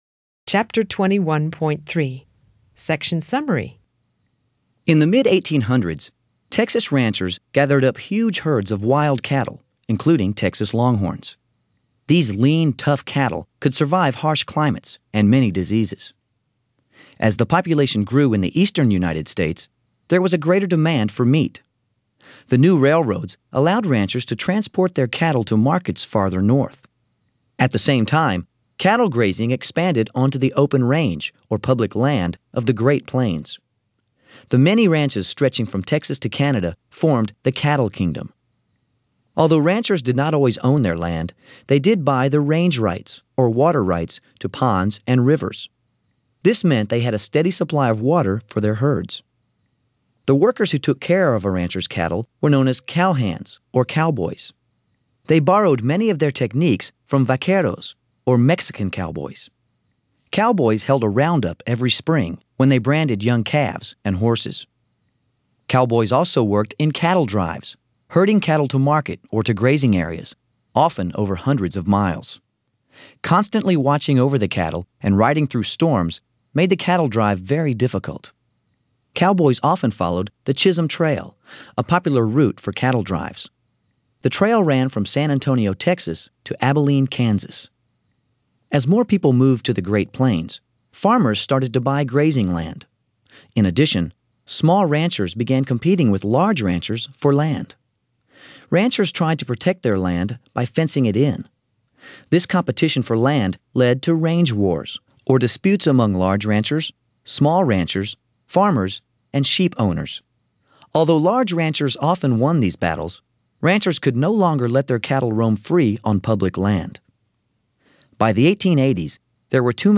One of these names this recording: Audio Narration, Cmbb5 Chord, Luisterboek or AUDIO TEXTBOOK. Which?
AUDIO TEXTBOOK